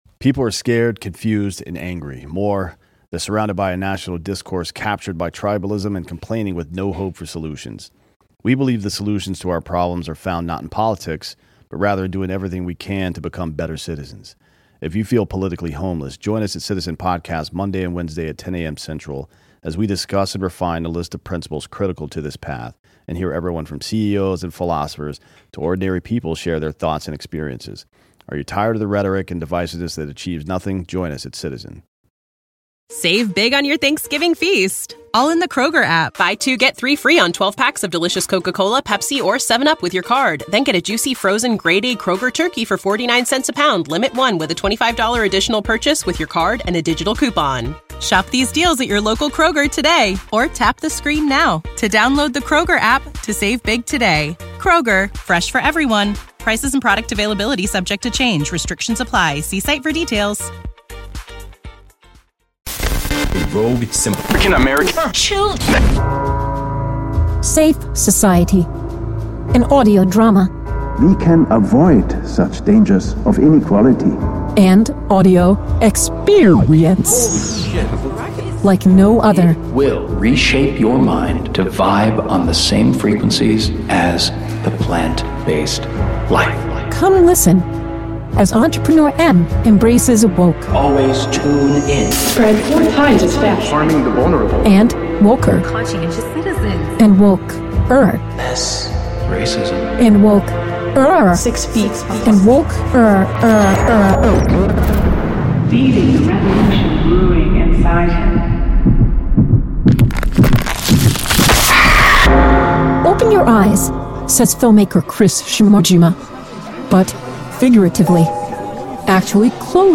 Safe Society - the audio drama - trailer